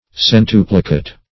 Search Result for " centuplicate" : The Collaborative International Dictionary of English v.0.48: Centuplicate \Cen*tu"pli*cate\, v. t. [imp.
centuplicate.mp3